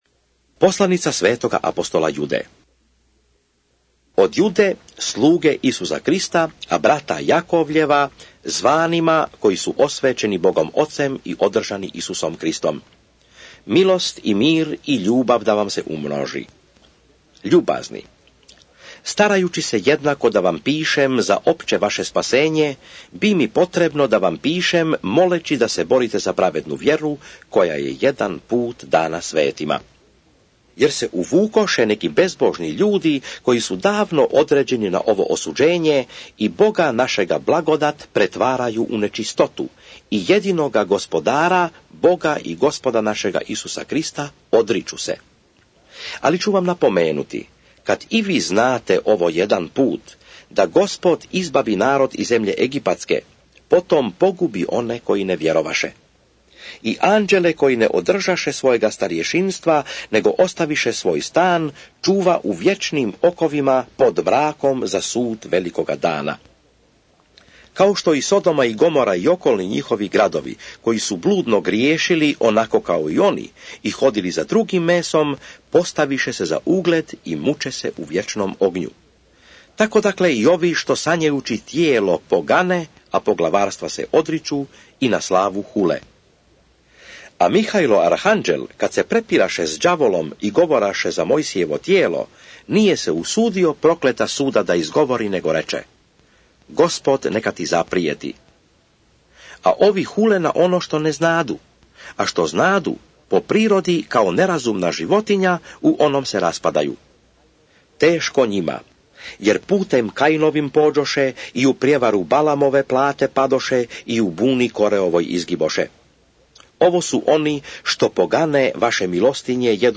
Juda / SVETO PISMO - čitanje - mp3